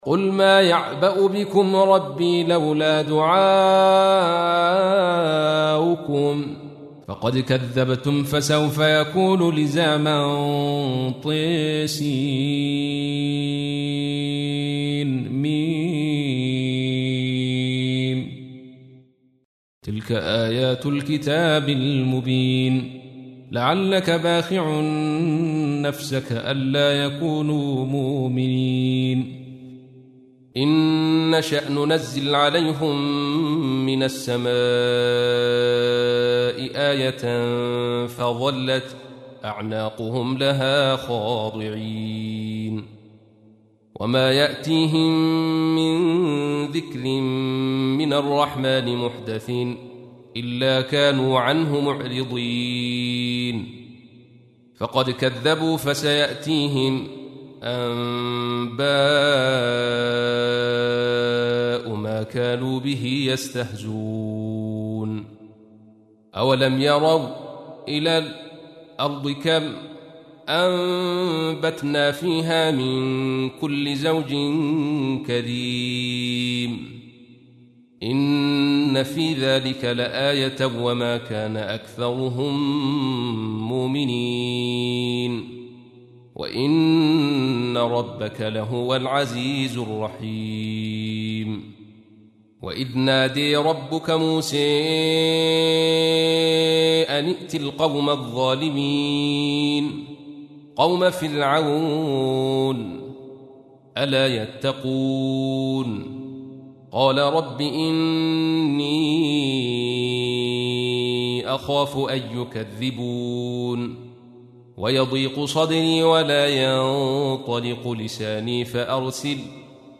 تحميل : 26. سورة الشعراء / القارئ عبد الرشيد صوفي / القرآن الكريم / موقع يا حسين